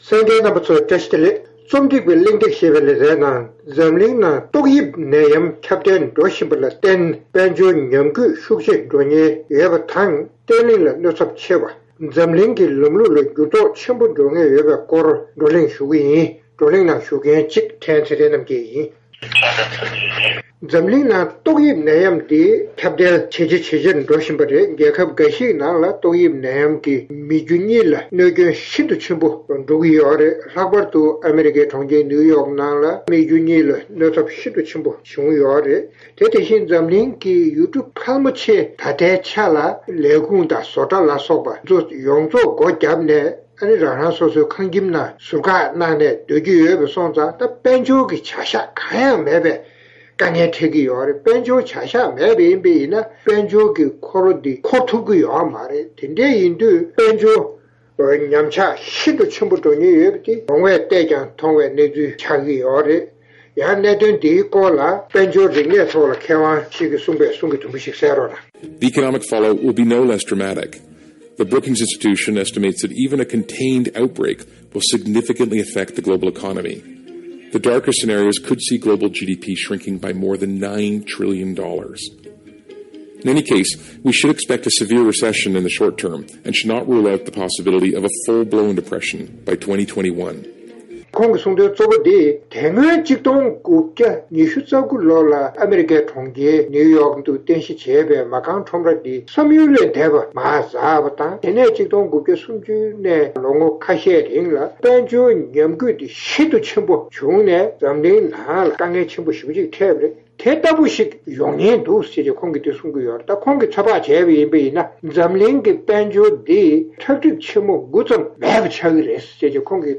བགྲོ་གླེང